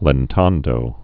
(lĕn-tändō)